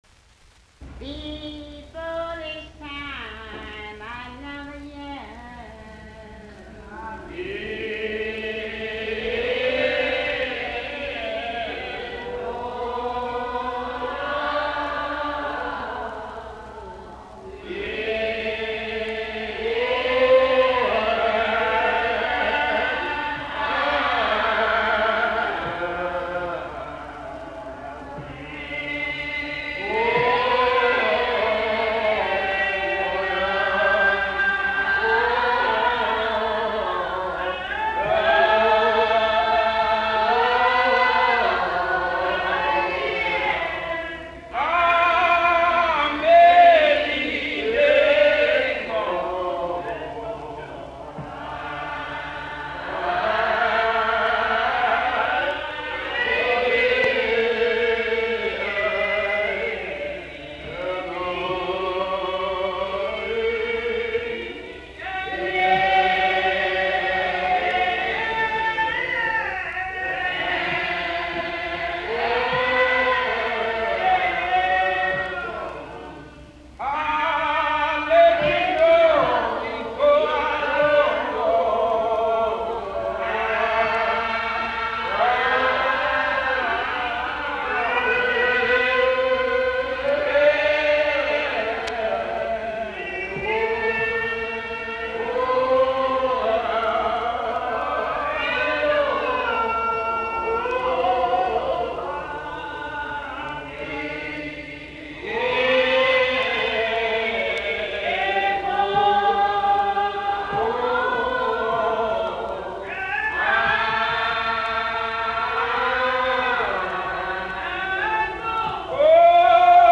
Music from the south : field recordings taken in Alabama, Lousiana and Mississippi.
I know the Lord, he heard my prayers - chant